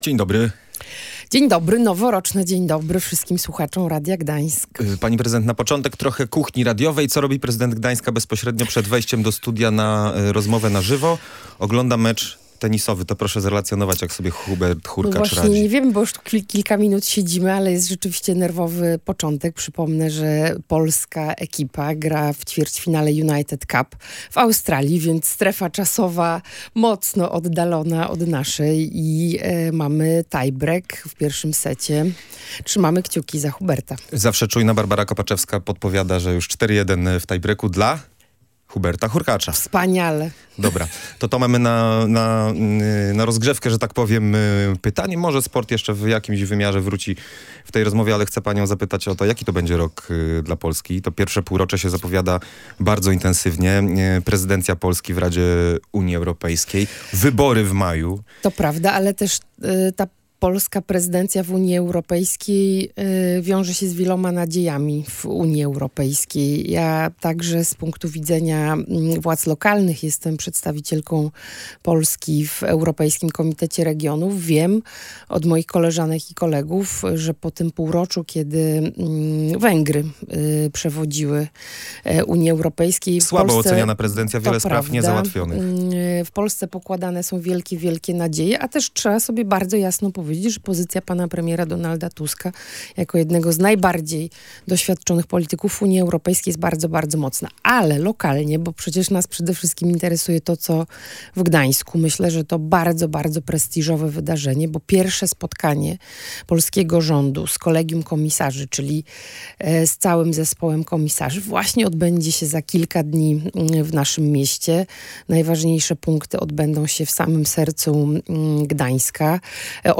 – To ważne nie tylko wizerunkowo – mówiła na naszej antenie Aleksandra Dulkiewicz.